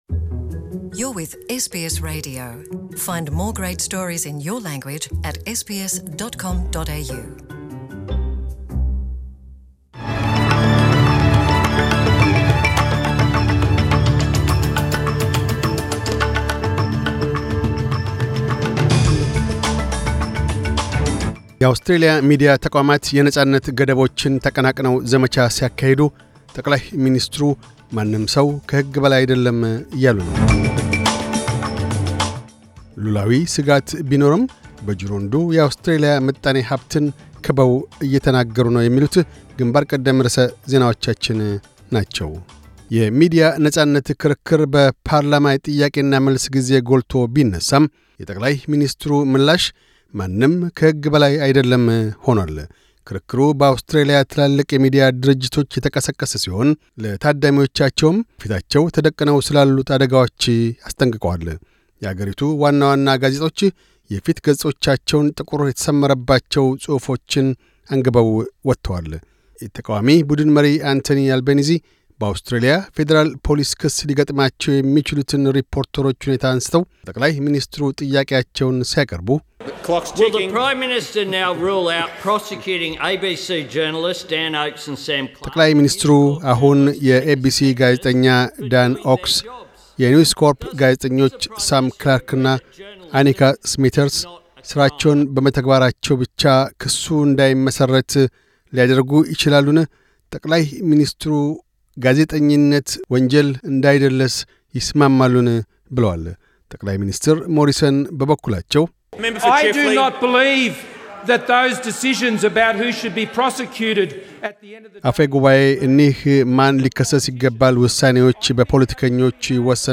News Bulletin 2010